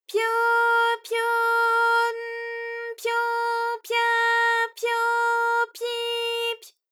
ALYS-DB-001-JPN - First Japanese UTAU vocal library of ALYS.
pyo_pyo_n_pyo_pya_pyo_pyi_py.wav